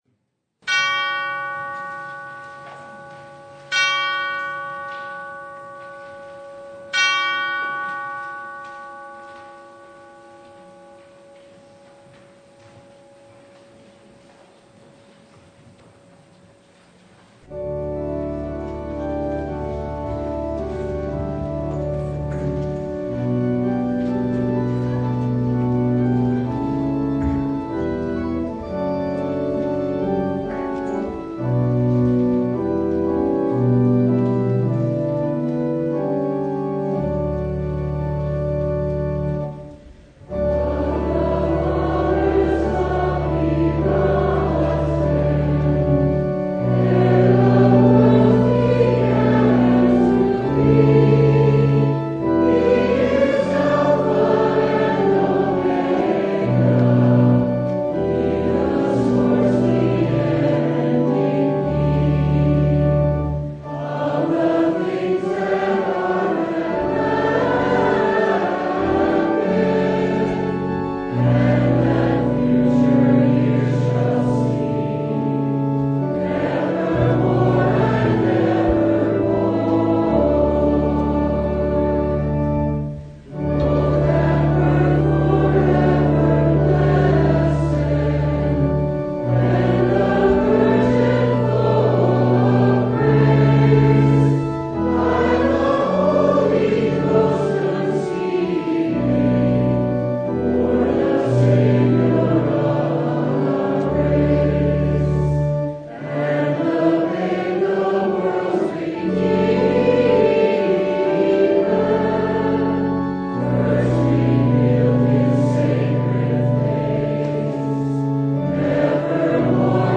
Passage: Luke 2:22-40 Service Type: Sunday